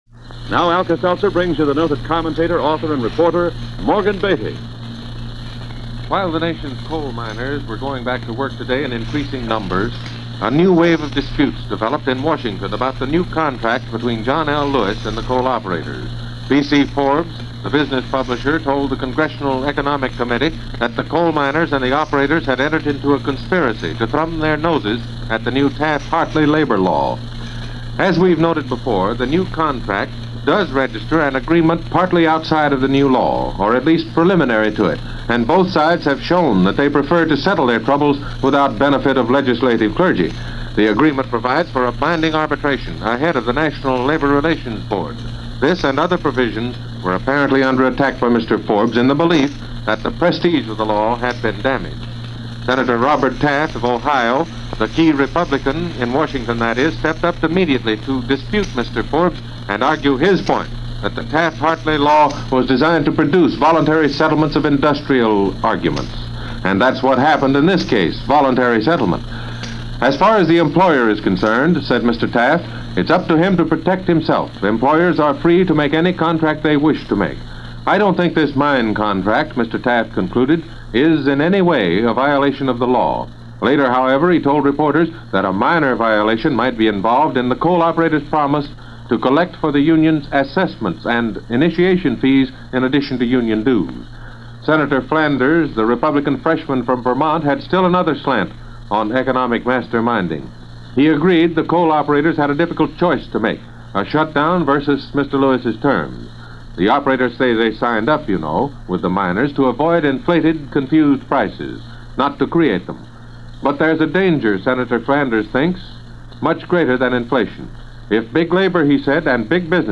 The World: Squabbles, Strikes And Strife - July 10, 1947 - As presented by Alka-Seltzer's News of The World - NBC Radio